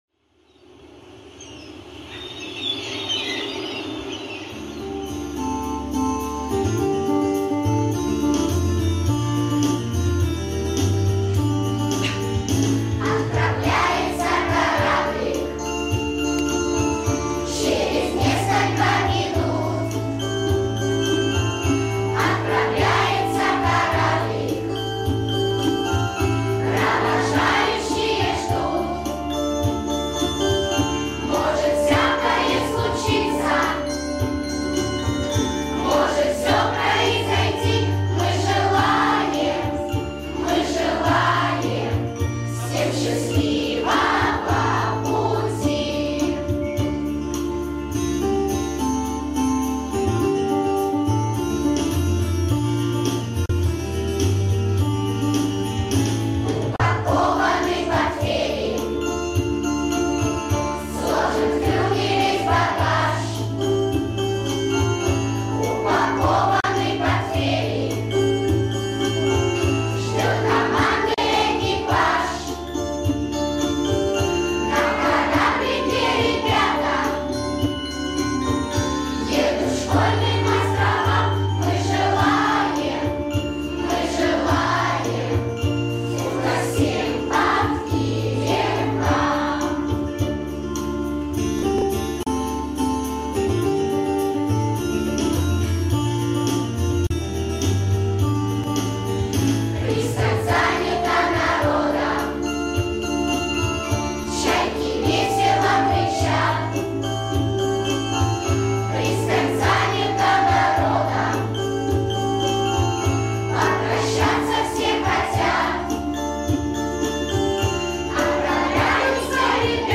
• Жанр: Детские песни
Детская песня